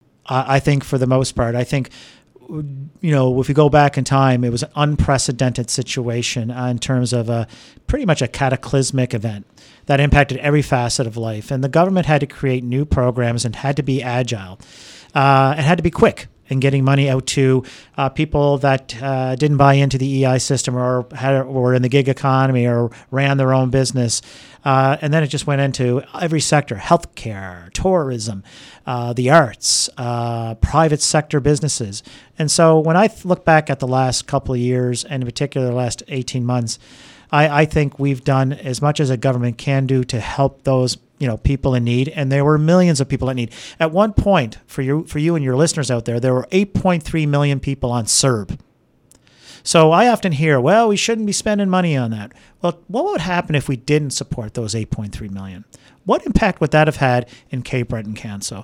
En entrevue sur les ondes de Radio CKJM à Chéticamp, le candidat libéral se définit comme un ardent défenseur des soins de santé et du développement économique communautaire.